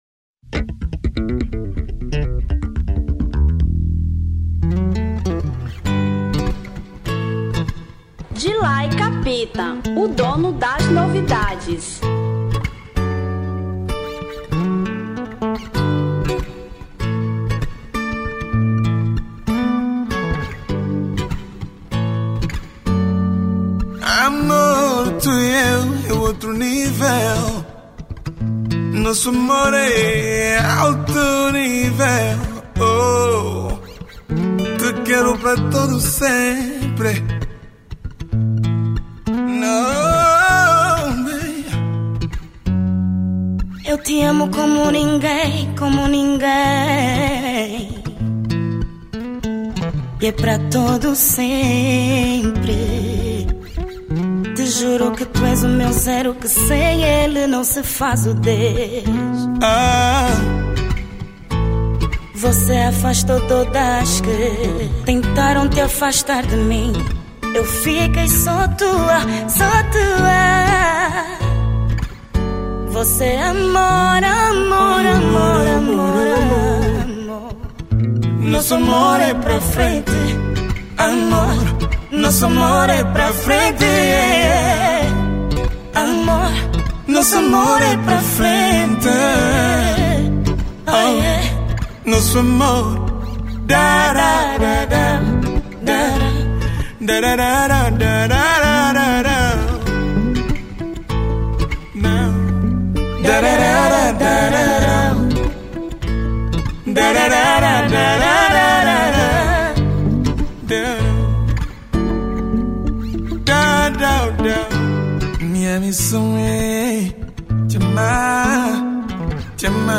Kizomba 2017